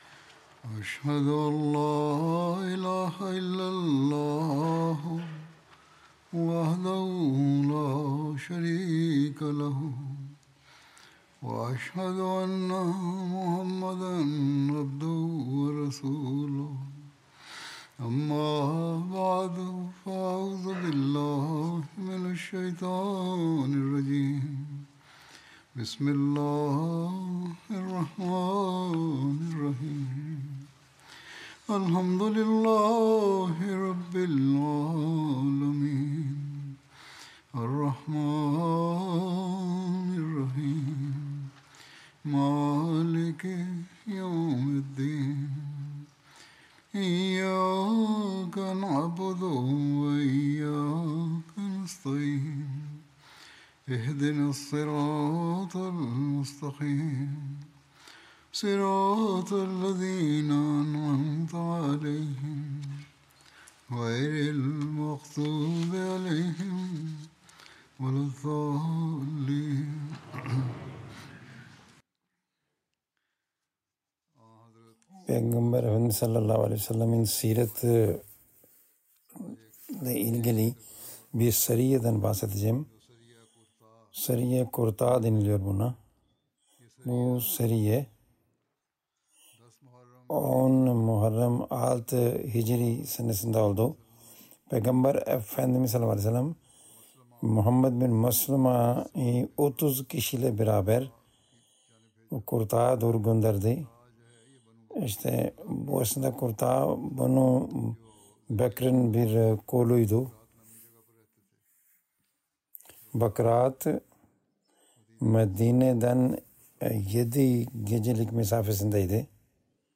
Turkish Translation of Friday Sermon delivered by Khalifatul Masih